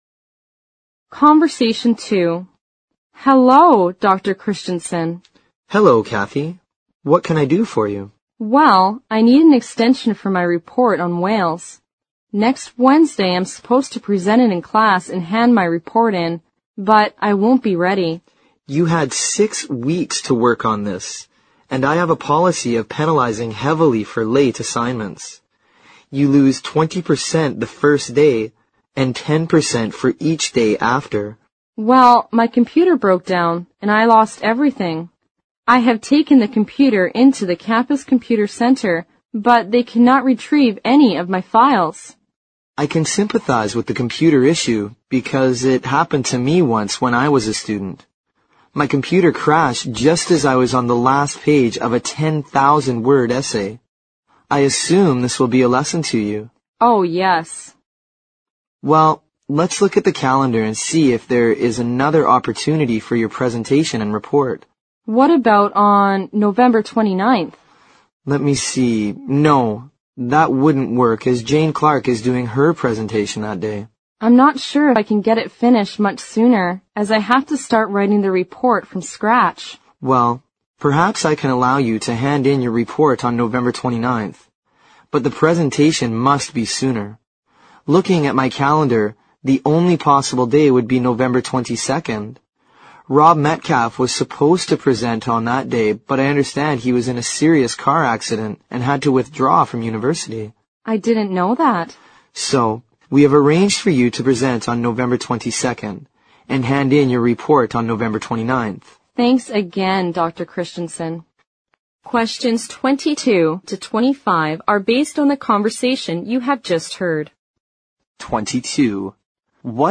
Conversation Two